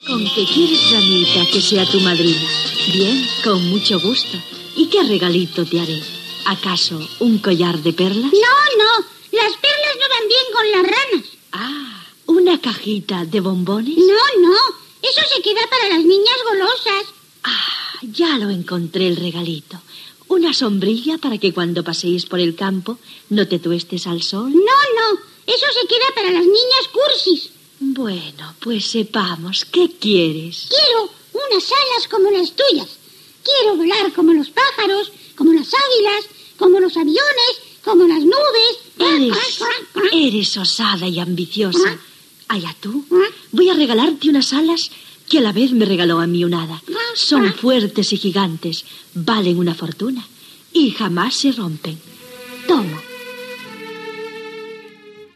Fragment d'un conte infantil protagonitzada per una granota i una fada
Infantil-juvenil